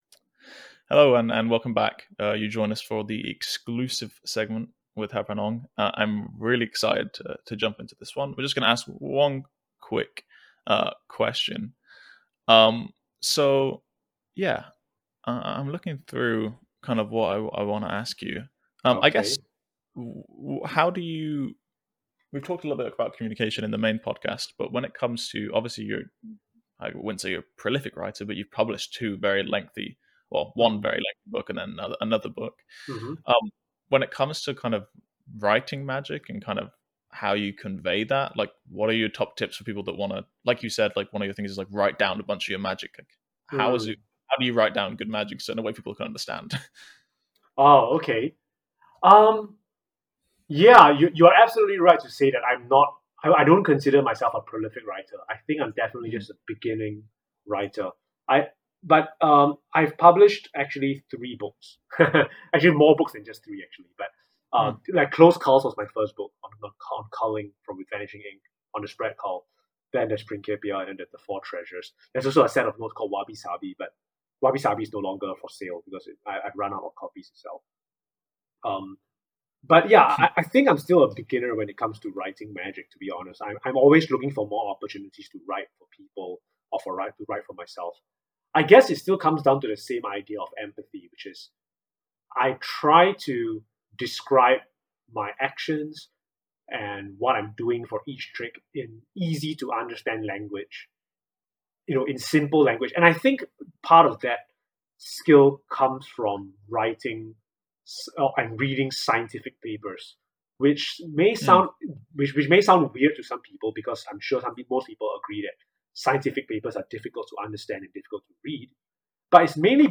Very recently we sat down and recorded an exclusive, quick 5-minute interview JUST for the Inner Circle.